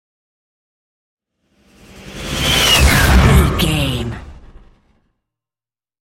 Airy whoosh large
Sound Effects
futuristic
whoosh
sci fi